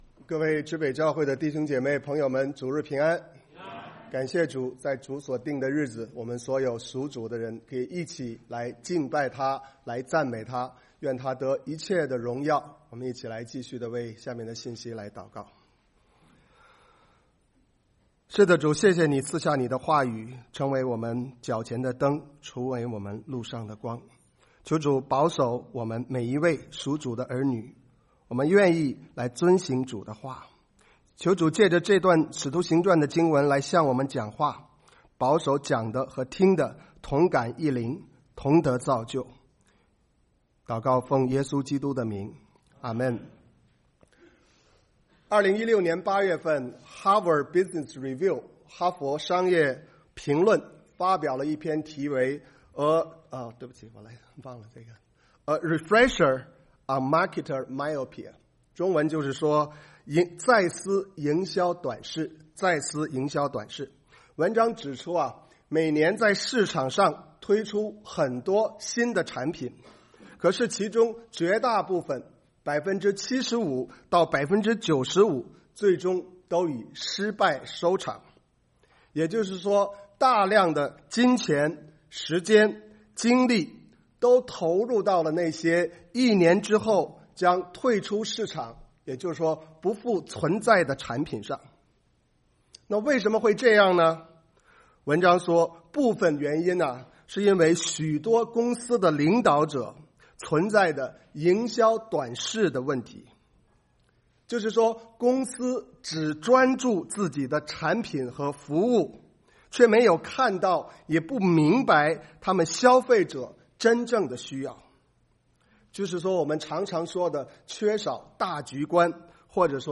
崇拜講道錄音